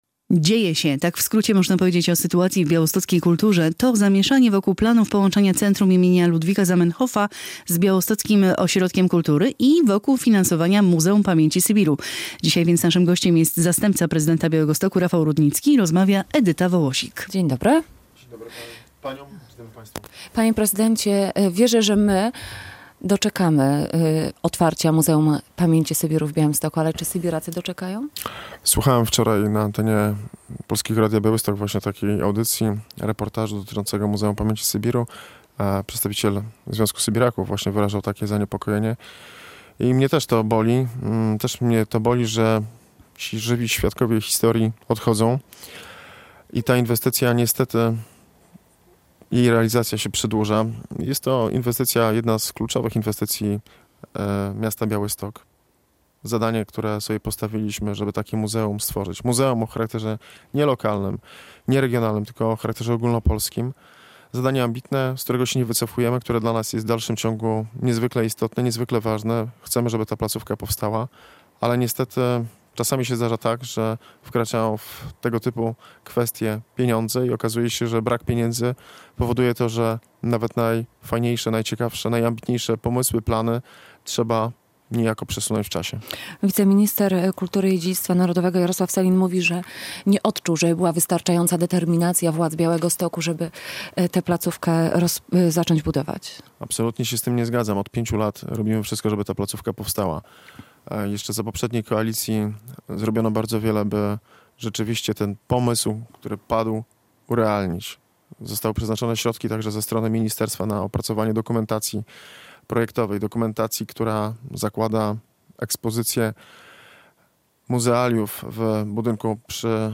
zastępca prezydenta Białegostoku